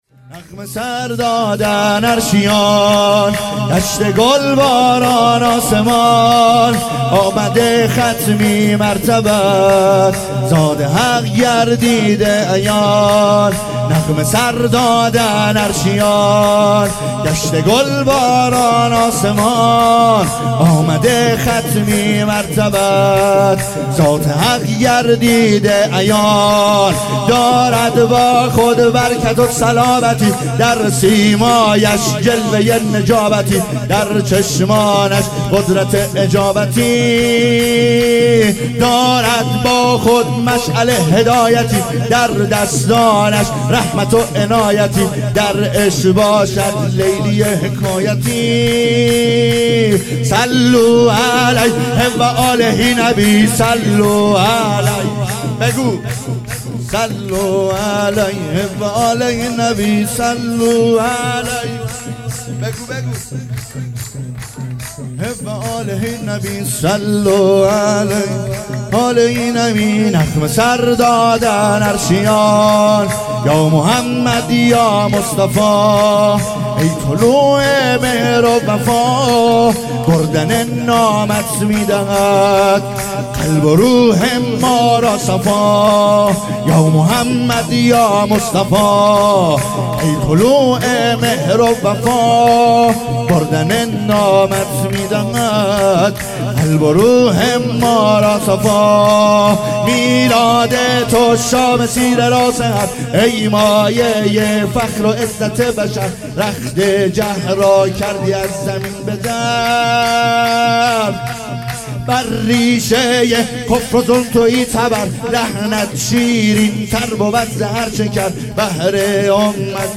شور
ولادت حضرت رسول(ص) و امام صادق (ع) ۱۴۰۱